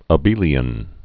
(ə-bēlē-ən)